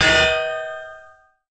anvil_land_low.ogg